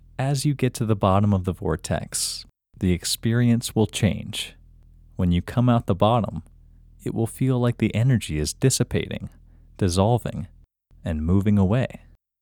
IN – Second Way – English Male 16